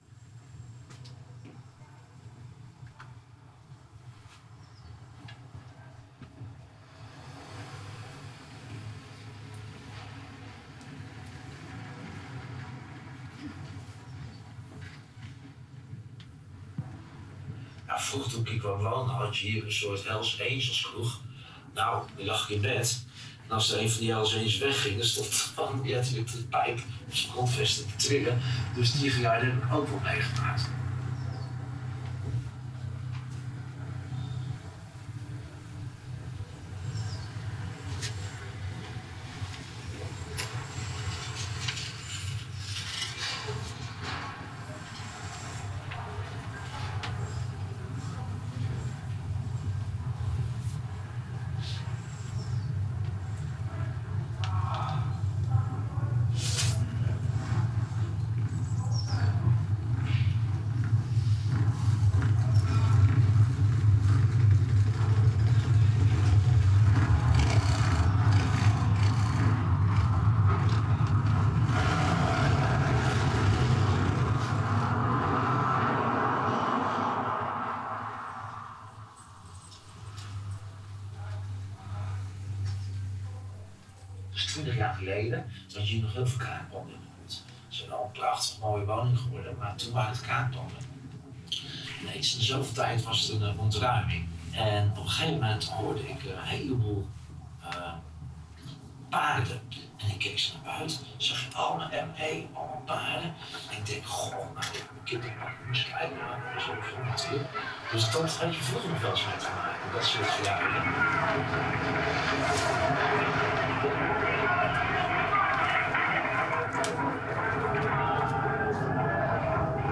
Urban Sound Lab deel 1 - Luisteravond #2 (deel 1) De afgelopen maanden hebben bewoners en ambachtslieden uit De Pijp en de Rivierenbuurt samen met kunstenaars van Urban Sound Lab geluiden verzameld en verhalen geschreven over wat ze in de buurt horen.
Tijdens de Urban Sound Lab Luisteravond #2 nemen ze jou mee op een auditieve reis langs hun persoonlijke geluiden en verhalen.